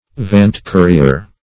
Search Result for " vant-courier" : The Collaborative International Dictionary of English v.0.48: Vant-courier \Vant"-cou`ri*er\ (v[.a]nt"k[=oo]`r[i^]*[~e]r), n. An avant-courier.